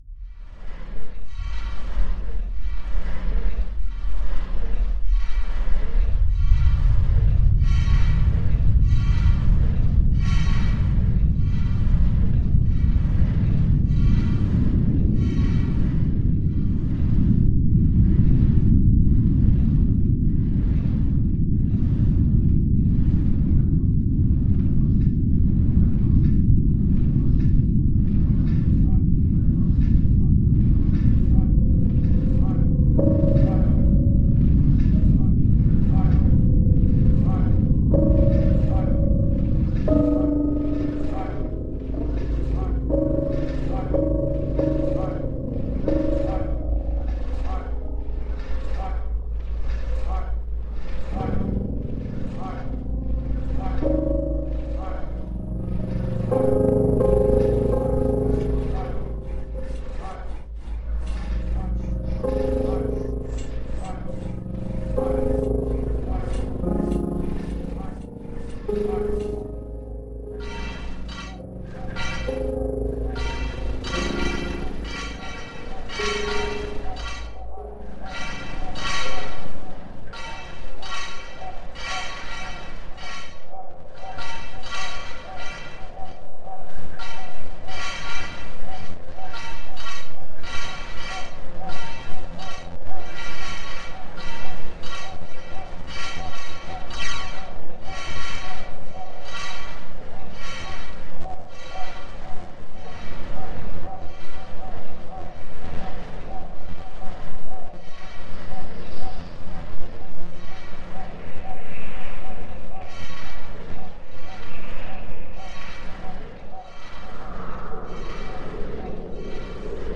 " Part of our project The Next Station, reimagining the sounds of the London Underground and creating the first ever tube sound map.